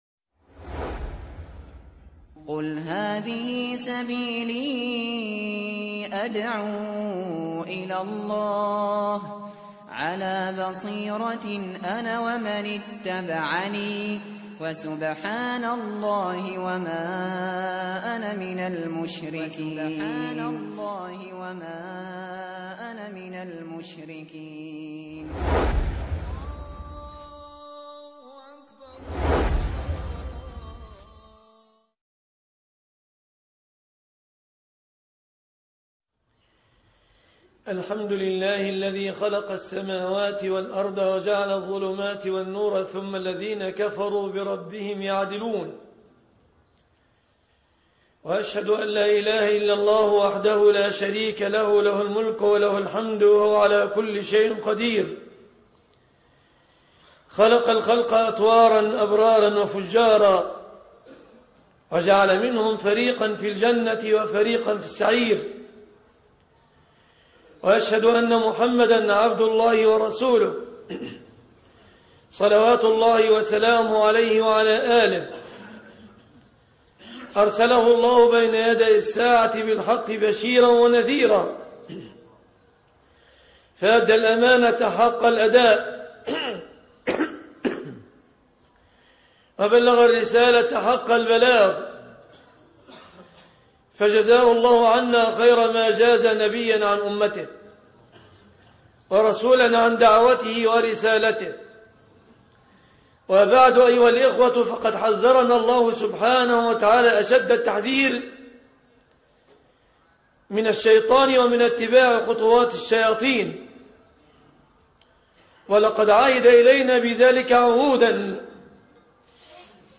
خطوات الشيطان- خطب الجمعة